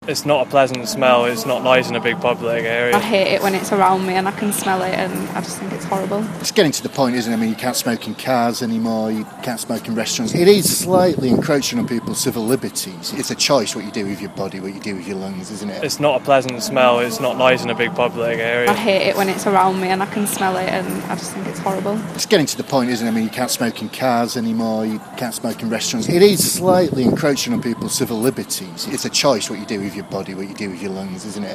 But how would such a move go down in Leeds? Radio Aire's been out to Millennium Square to find out.